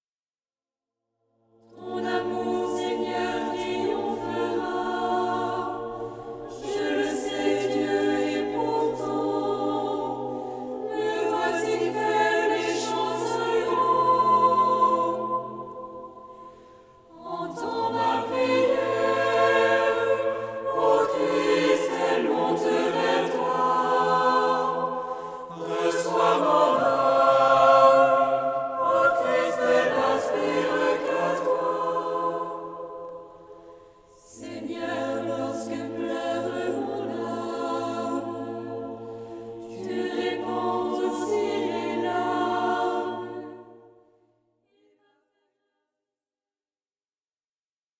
choeur